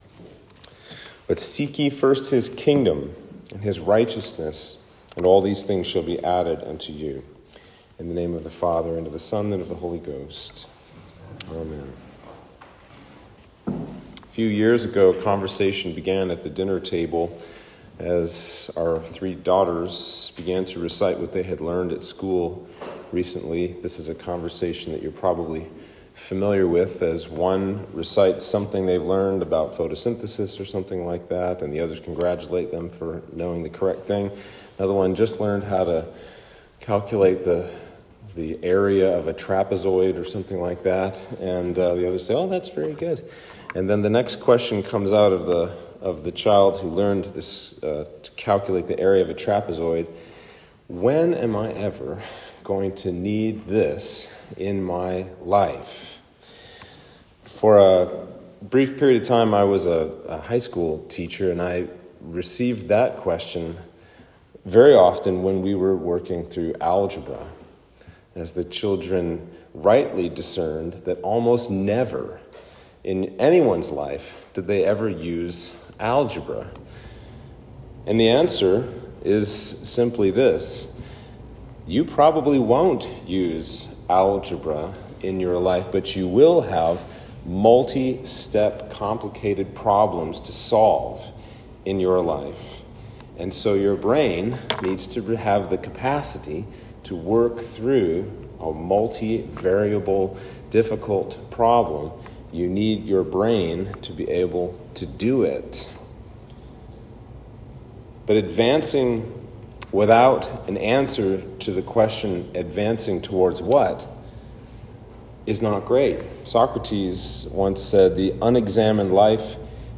Sermon for Trinity 15